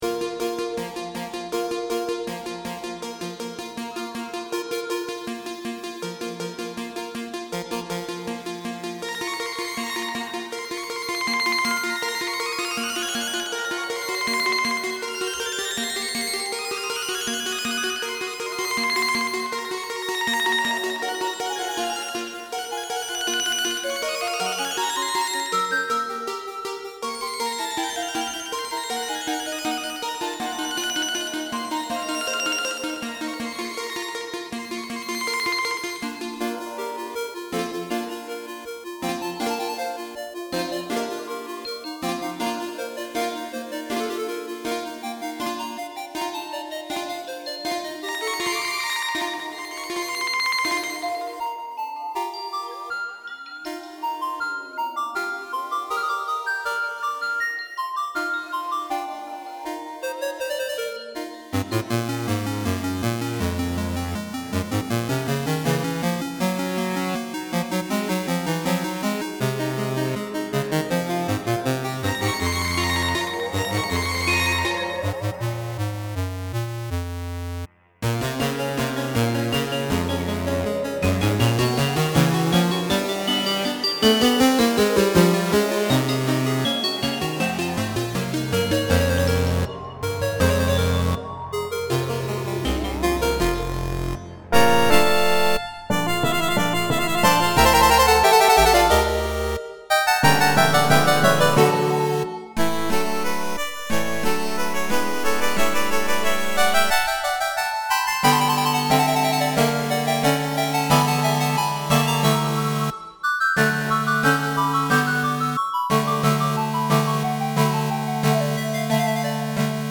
Alt...10th Symphony, 3rd Movement: Purgatorio by Gustav Mahler, in a blippy, echoey chiptune style meant to suggest a water-themed dungeon in a video game.